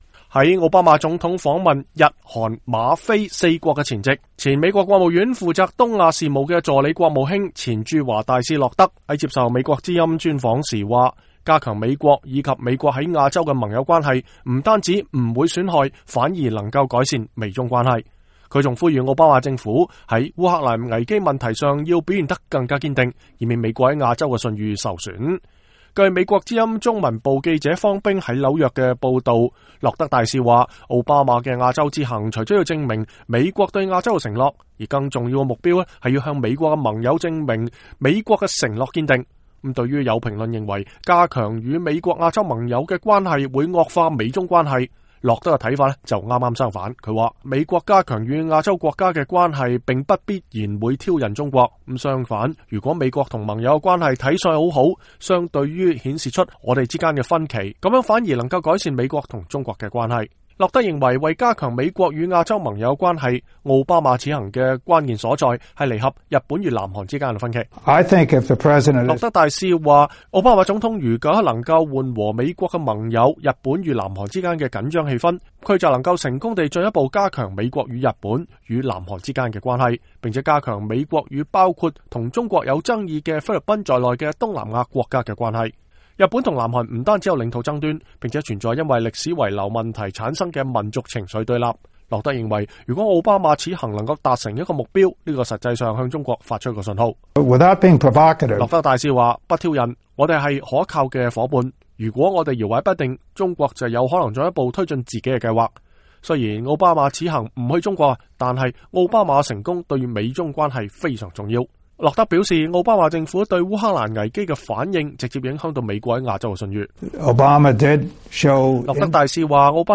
在奧巴馬總統訪問日、韓、馬、菲四國前夕，前任美國國務院負責東亞事務的助理國務卿、前任駐華大使洛德接受美國之音專訪表示，加強美國與其亞洲盟友的關係，不僅不會損害反而能改善美中關係。他還敦促奧巴馬政府在烏克蘭危機問題上表現得更加堅定，以免美國在亞洲的信譽受損。